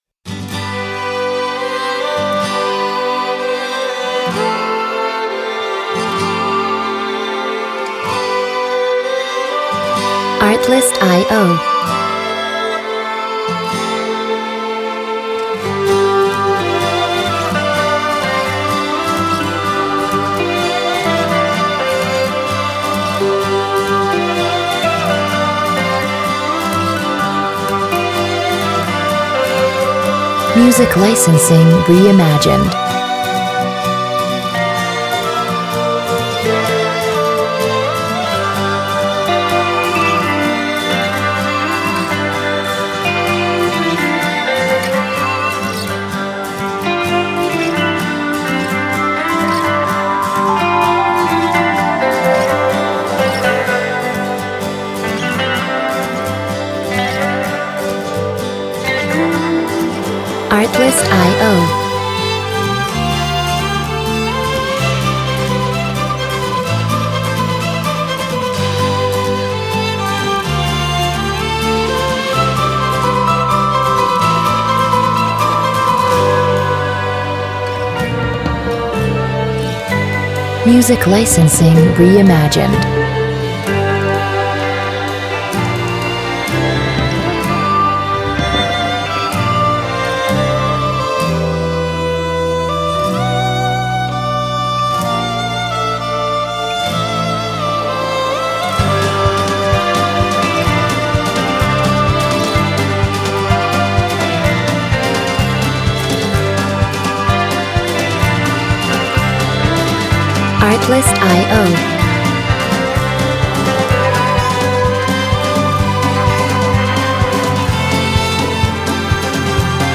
laidback, folk-pop tracks